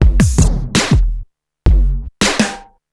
All these loops were created at loopasonic and are all original and copyright free.